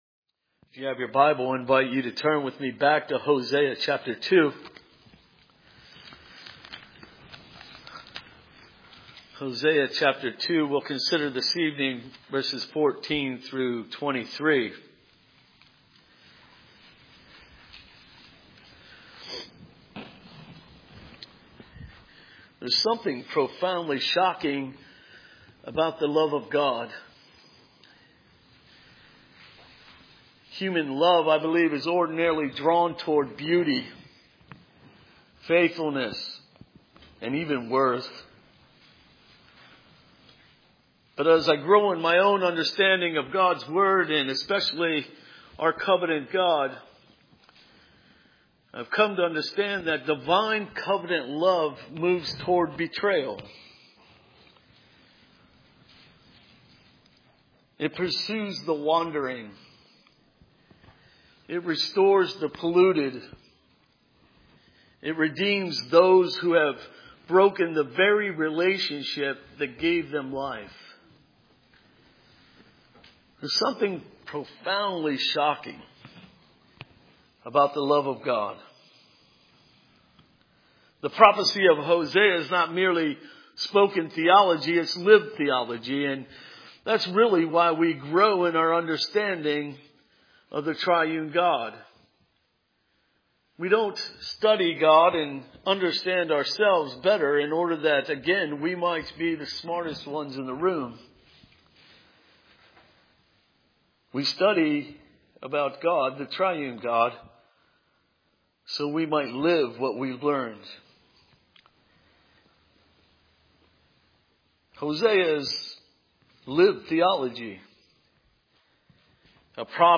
Hosea 2:14-23 Service Type: Sunday Evening Hosea 2:14-23 There is something profoundly shocking about the love of God.